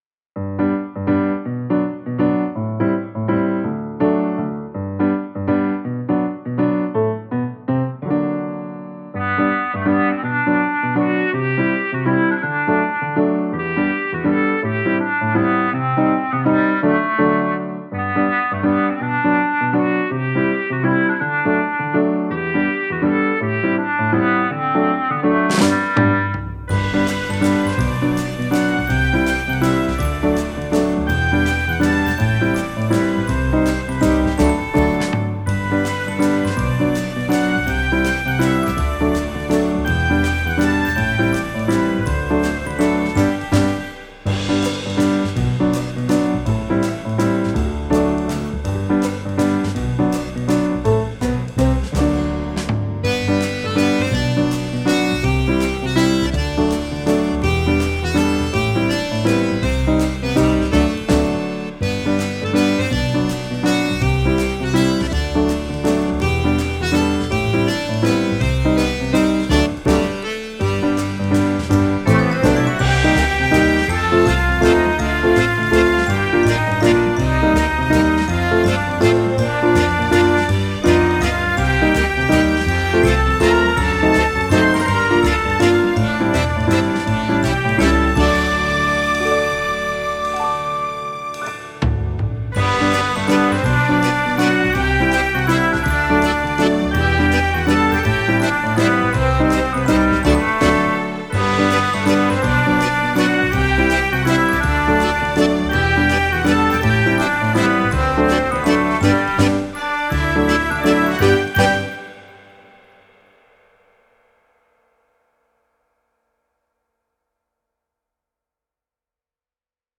ジャズ
ワルツ
ピアノ
明るい